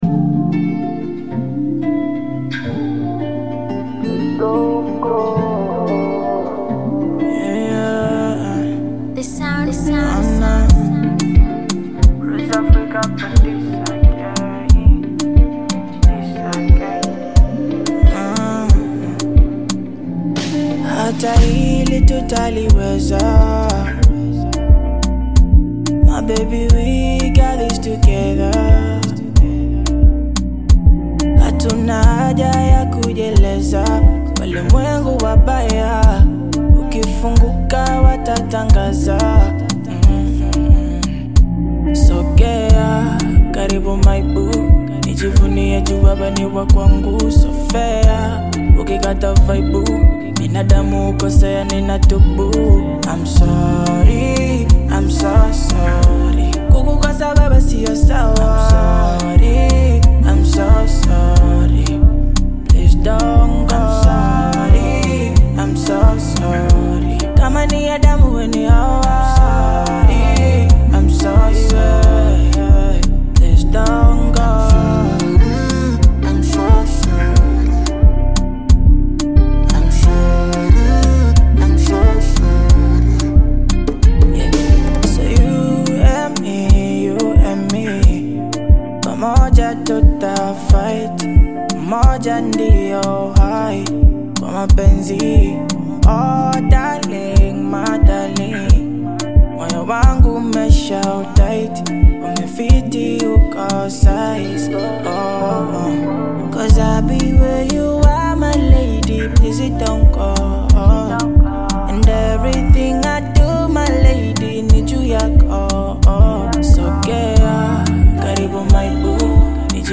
Genre: Bongo Flava